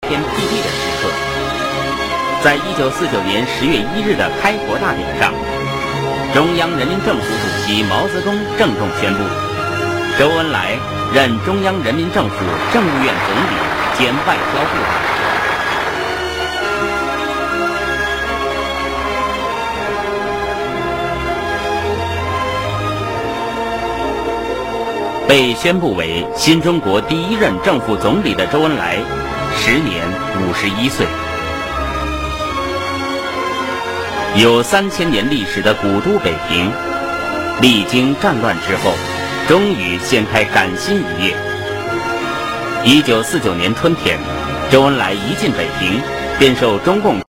这段录音的背景音乐是什么曲子？